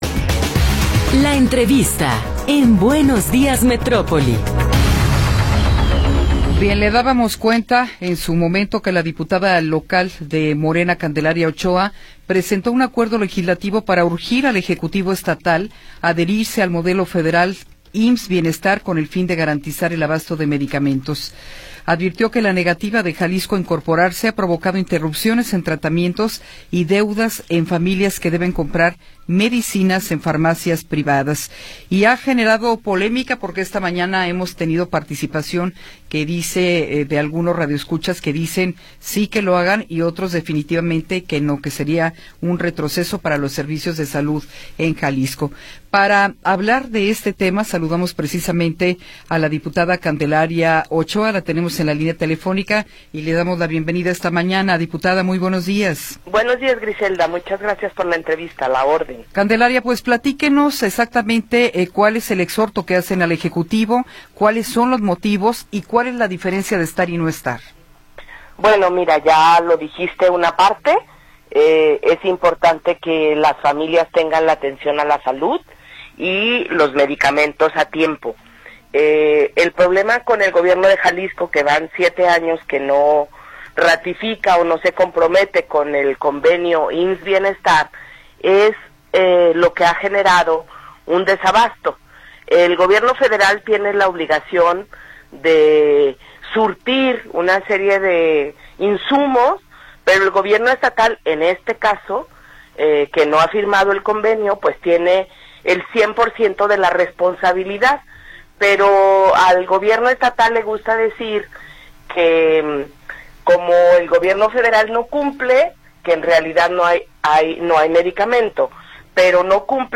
Entrevista con Candelaria Ochoa Ávalos